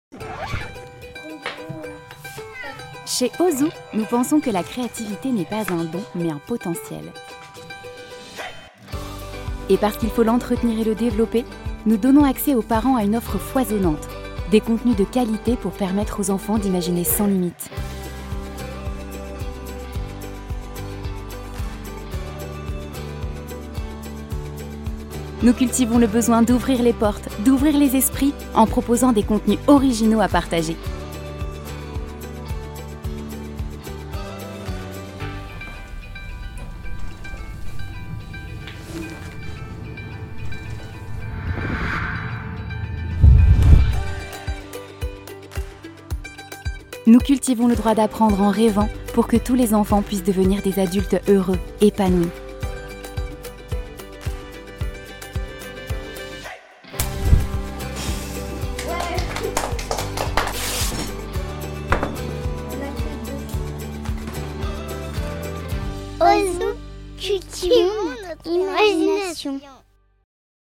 Sua entrega segura e acolhedora se adapta a comerciais, narrações e rádio, tornando-a uma escolha versátil para marcas que buscam serviços profissionais de locução.
Vídeos Corporativos
Microfone: Neumann TLM 103